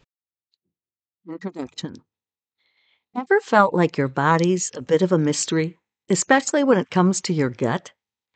Garbled recordings
My recordings are garbled in places though–not everywhere.
Here’s an unedited sample: